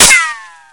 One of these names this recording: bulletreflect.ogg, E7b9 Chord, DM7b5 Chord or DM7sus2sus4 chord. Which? bulletreflect.ogg